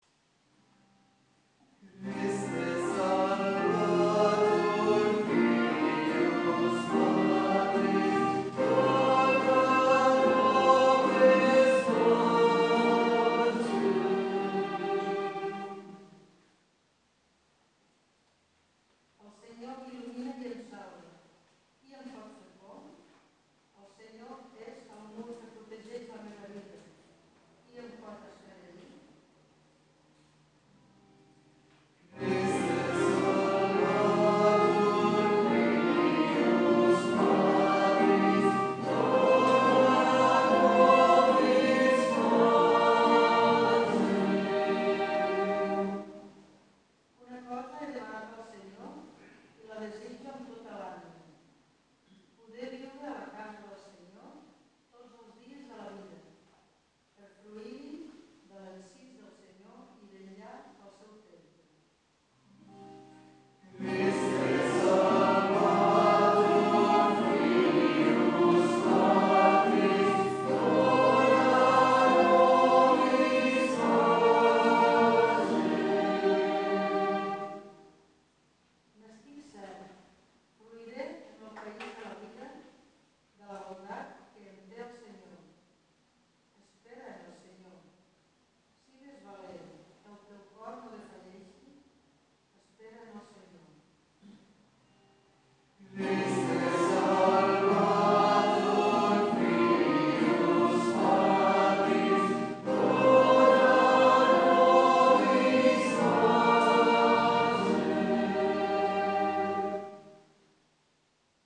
Pregària de Taizé
Ermita de Sant Simó - Diumenge 26 de gener de 2014